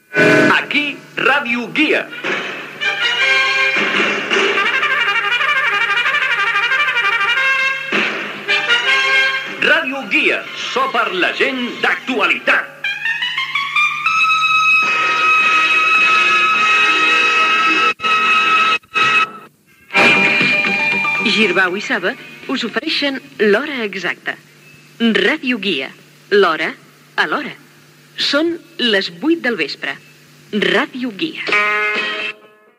Indicatiu, publicitat i hora Gènere radiofònic Publicitat